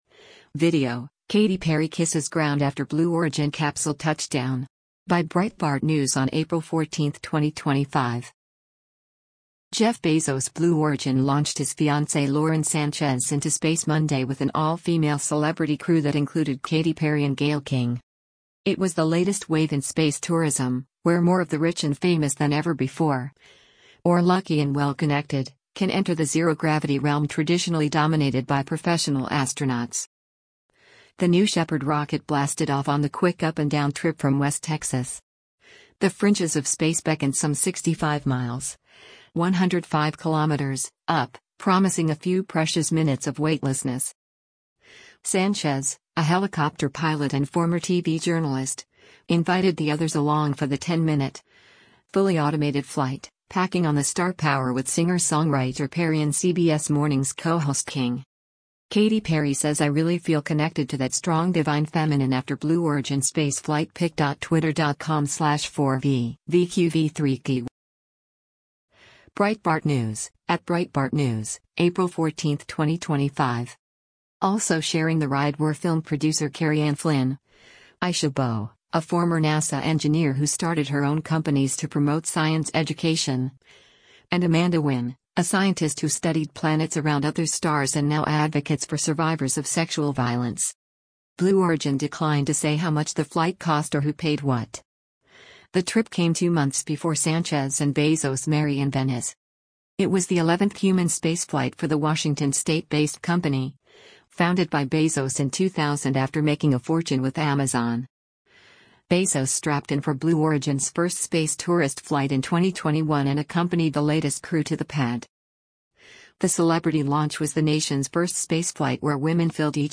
Bezos opened the capsule’s hatch minutes after touchdown, embracing Sanchez, the first one out. As they emerged, Perry and King kneeled and kissed the ground.